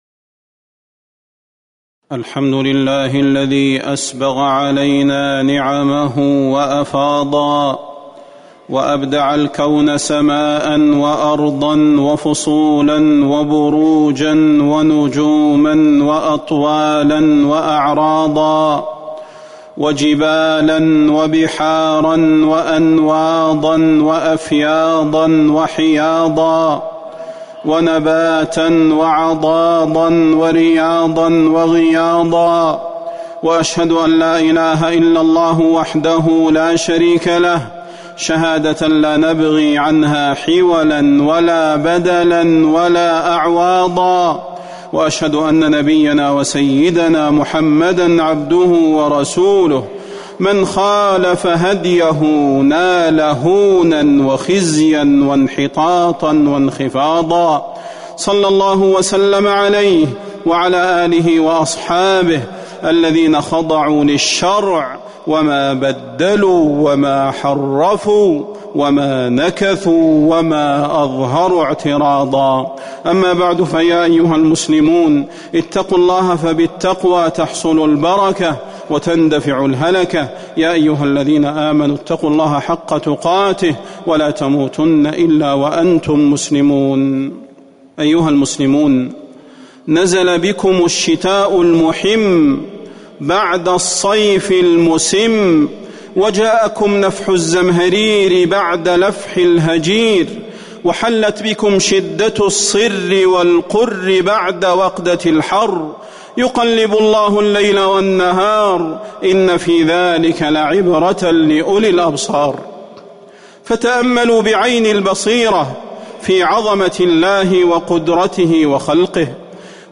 فضيلة الشيخ د. صلاح بن محمد البدير